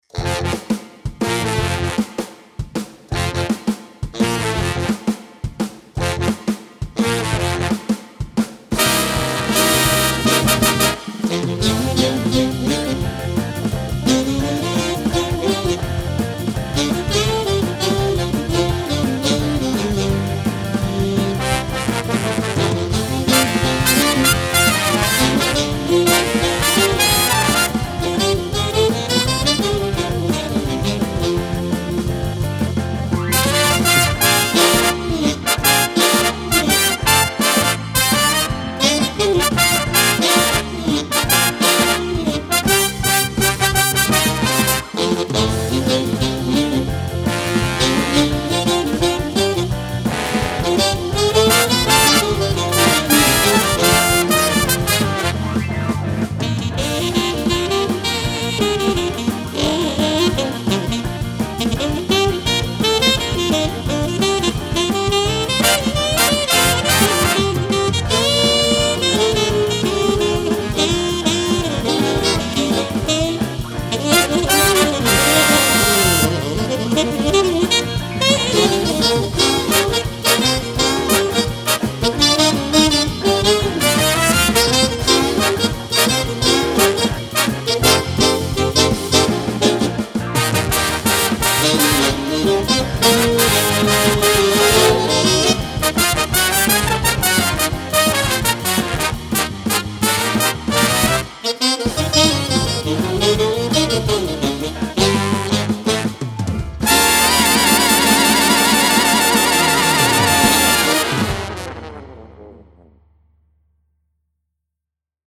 Highest Notes: Alto sax: Eb3, Trumpet 1: B3, Trombone 1: A3